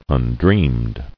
[un·dreamed]